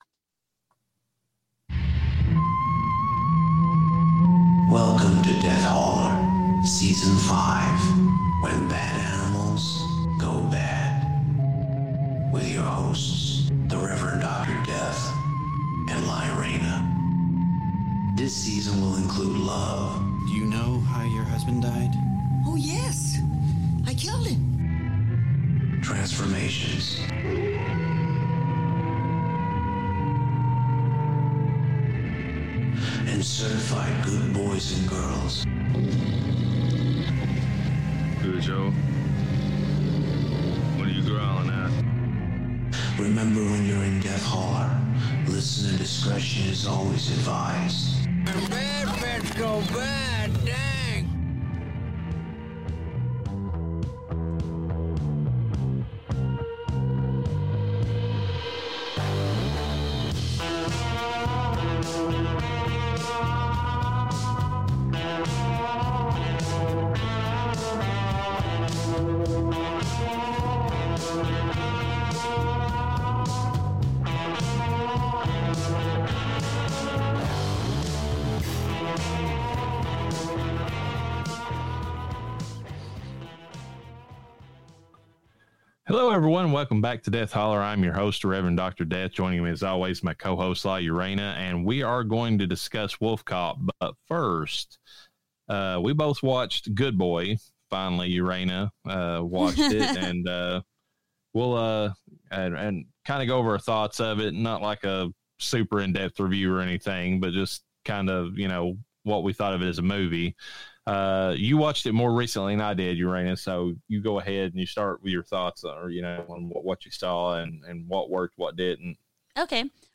🦇 Stick around after the closing song for a hilarious outtake when the hosts completely lose it.